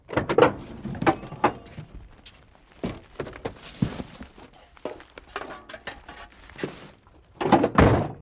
wreck_1.ogg